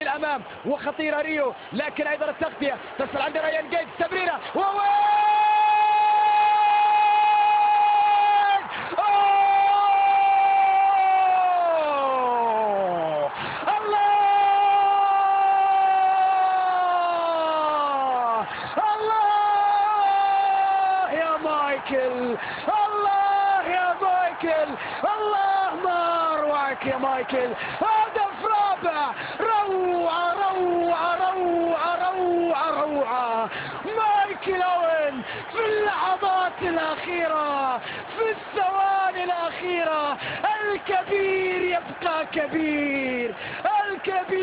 ببخشید که کیفیتش پایینه